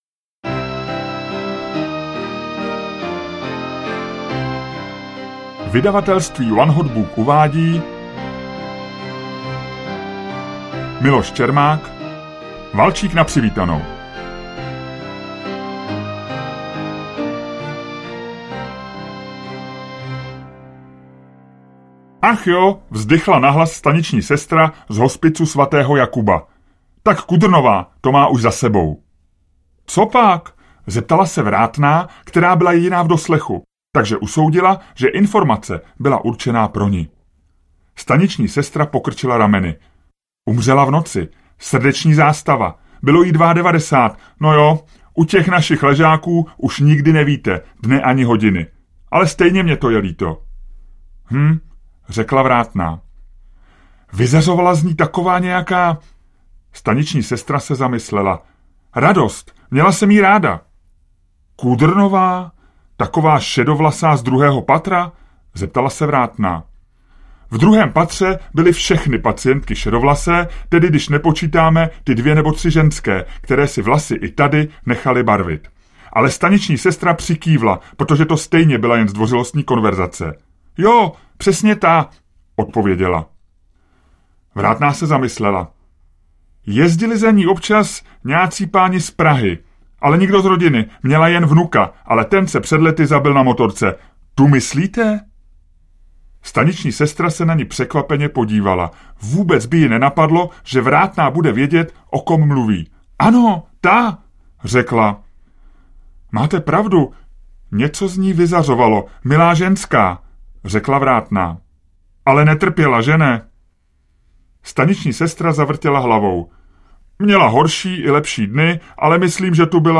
Valčík na přivítanou audiokniha
Ukázka z knihy